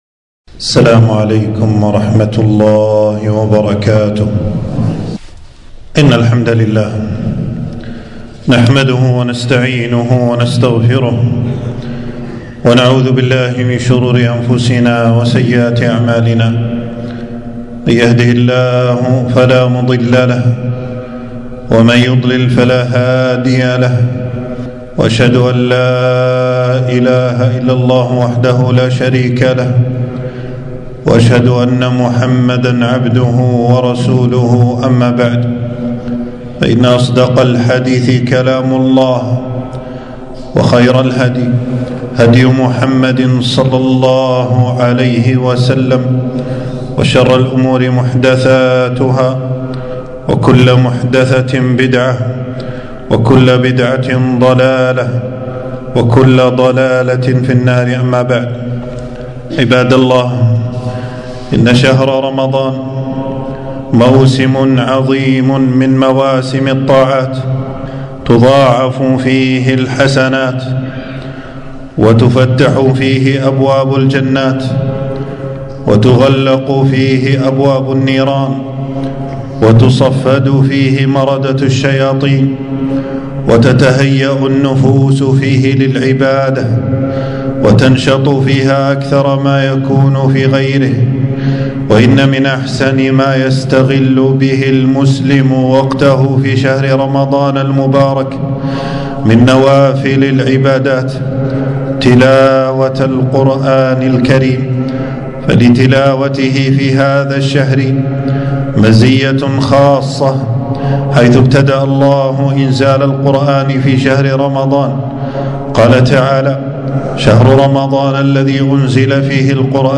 تنزيل تنزيل التفريغ خطبة بعنوان: تلاوة القرآن وقيام ليل رمضان.
حفظه الله تعالى المكان: خطبة في يوم 9 رمضان 1446هـ في مسجد السعيدي بالجهرا.